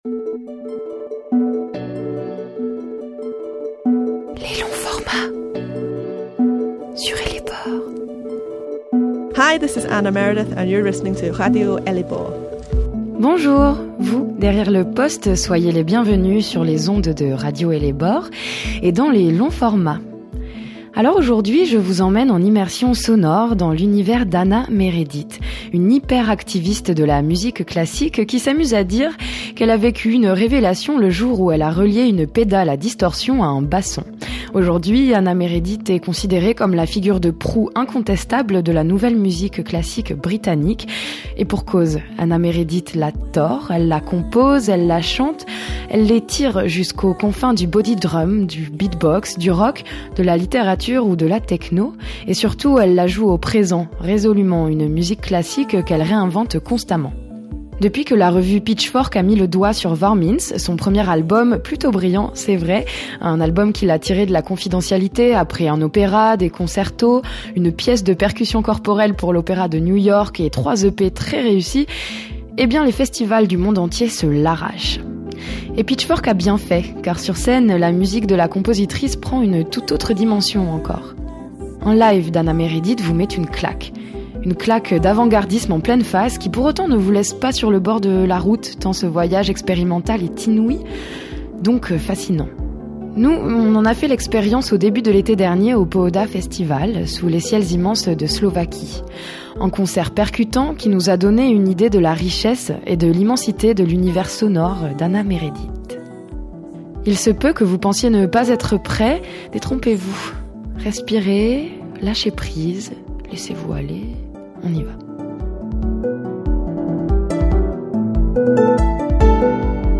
Une interview à écouter ( en immersion dans le son d’Anna Meredith) ou à lire ci-dessous.